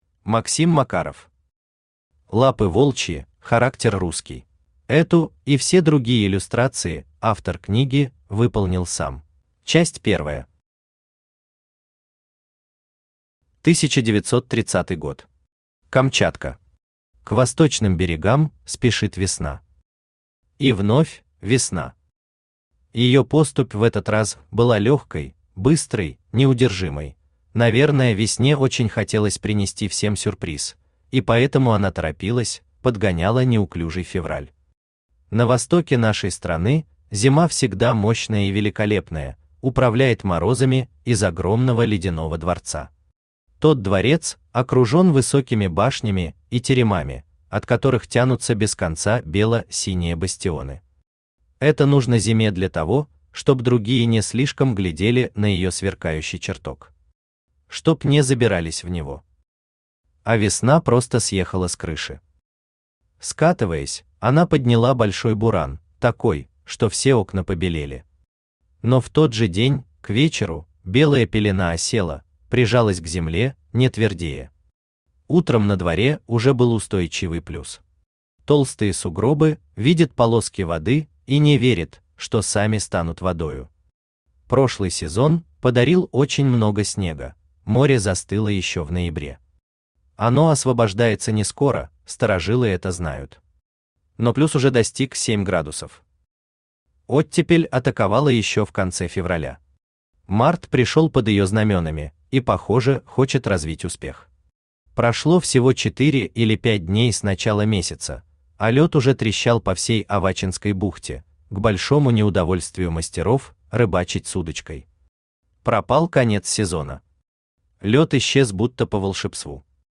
Аудиокнига Лапы волчьи, характер русский | Библиотека аудиокниг
Aудиокнига Лапы волчьи, характер русский Автор Максим Сергеевич Макаров Читает аудиокнигу Авточтец ЛитРес.